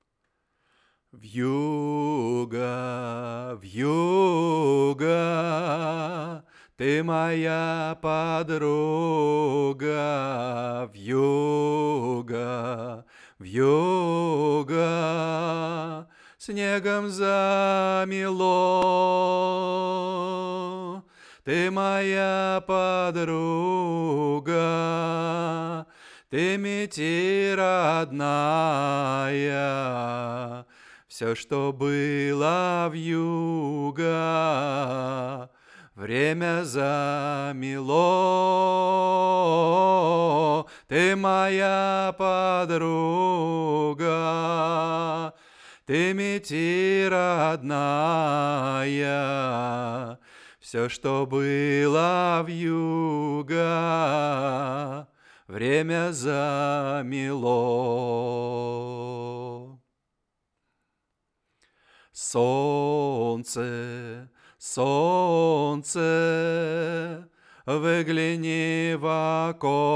Записал в аблтон9 вокал и аккомпанимент под ямаха пср620 в живую на разные треки.
Но совместное прослушивание не очень устраивает, вокал как бы идёт отдельно от аккомпанимента.
Голос не мой, хотя пою я. Из личной тех. критики это заметное из гитарного жаргона тремоло или дрожание длинных нот.
Писал на микрофон с 10-15 см через фильтр с двумя слоями капрона.